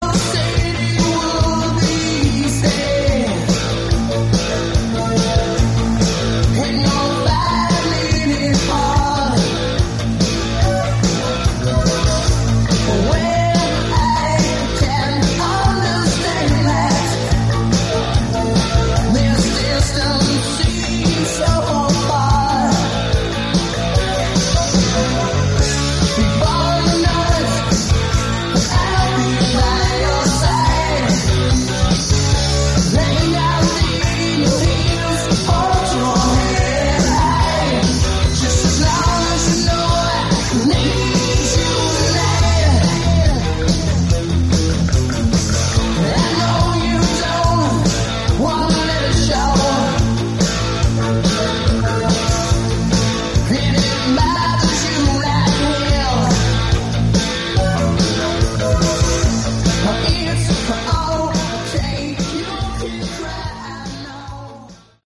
Category: Hard Rock
lead vocals